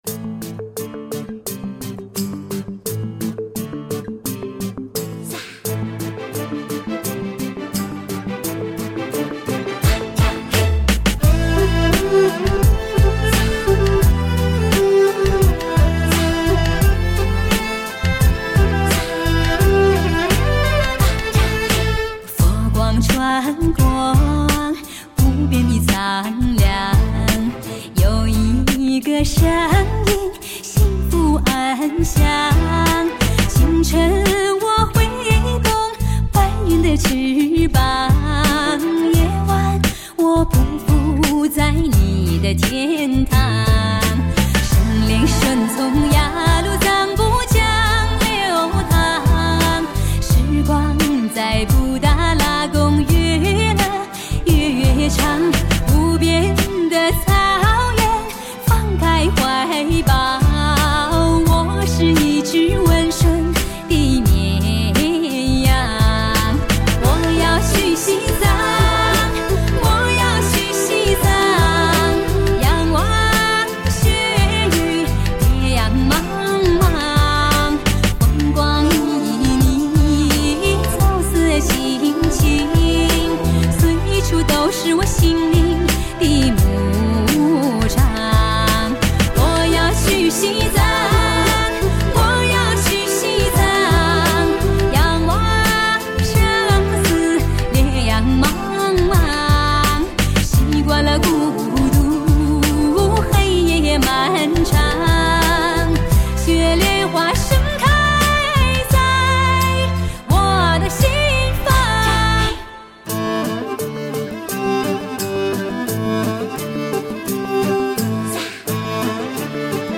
顶级音响测试天碟 国内最佳声乐表现力的发烧唱将
低唱，舒缓透明，融合淡淡的诗意。